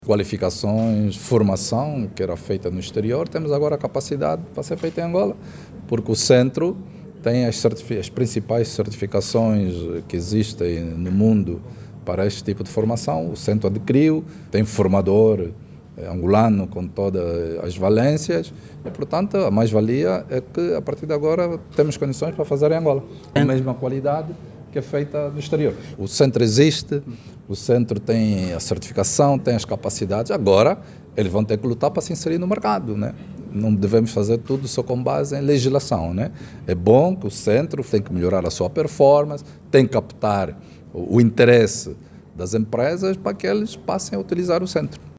O ministro dos Recursos Minerais, Petróleos e Gás, Diamantino Azevedo, assegura que o centro de perfuração, completação e controlo de poços desempenhará tarefas de grande importância para o sector.